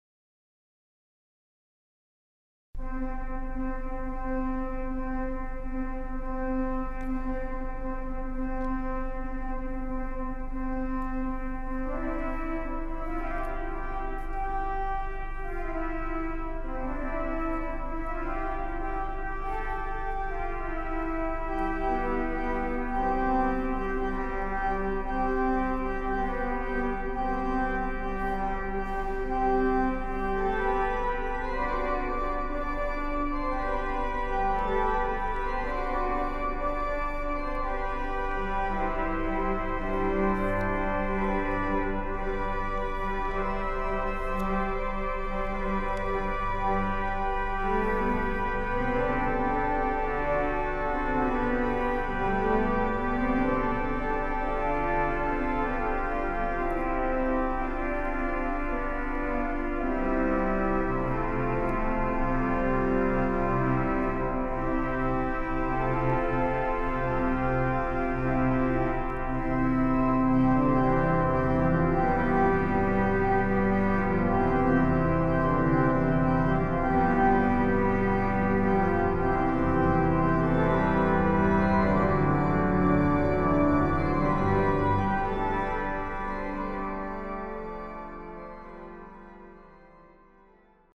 Basilica del Sacro Cuore di Cristo Re
Concerto organistico in onore dei nuovi Santi Papa Giovanni XXIII e Papa Giovanni Paolo II
Batalla Imperial I, in do maggiore